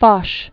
(fôsh, fŏsh), Ferdinand 1851-1929.